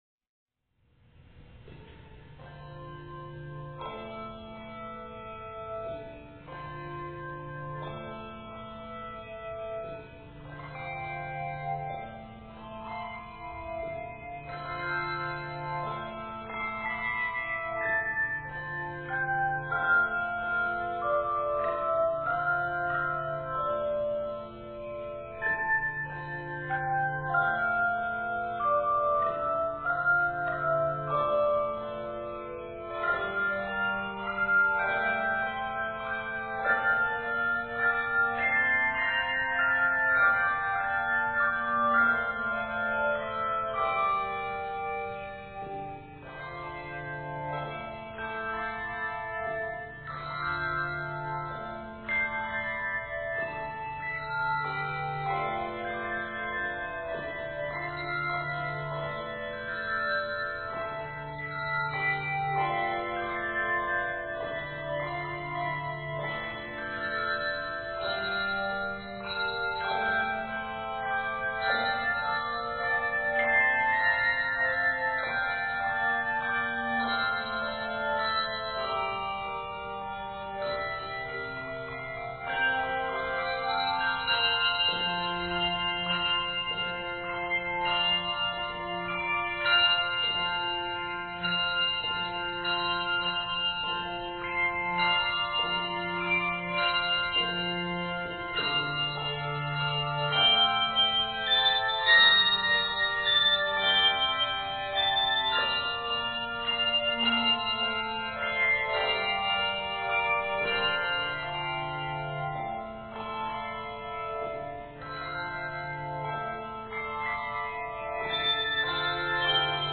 lovely and mysterious setting